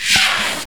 SI2 SAXNOISE.wav